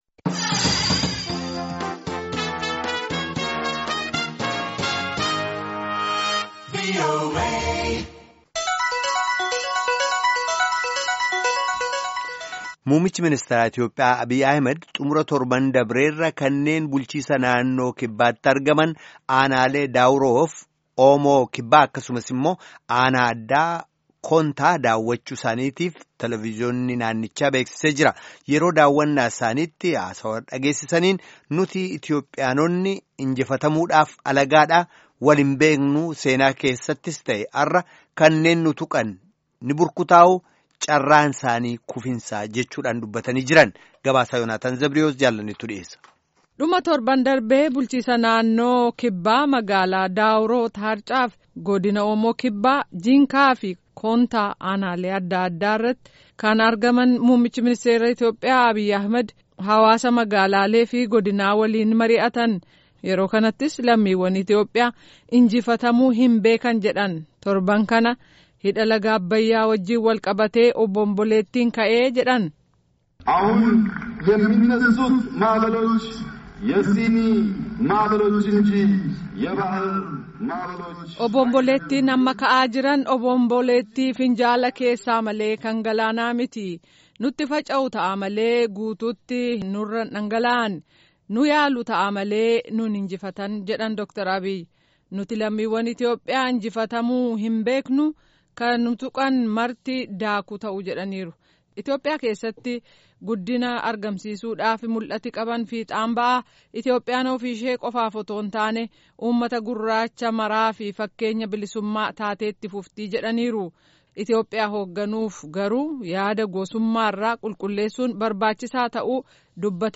Gabaasaa